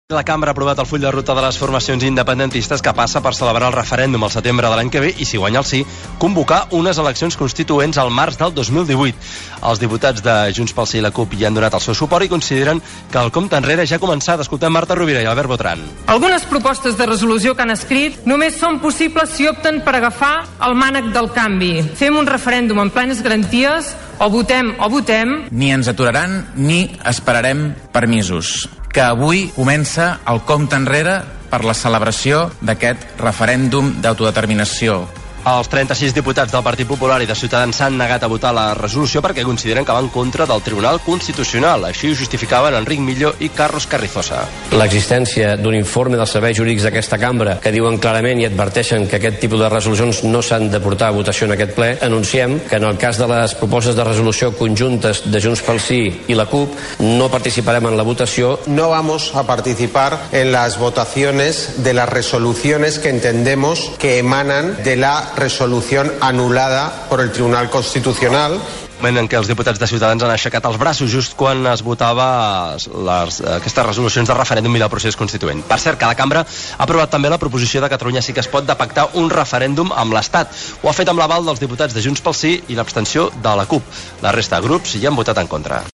Aprovació al Parlament de Catalunya de la proposta de Referèndum d'autodeterminació i del procés constituent, amb declaracions dels diferents representants polítics.
Informatiu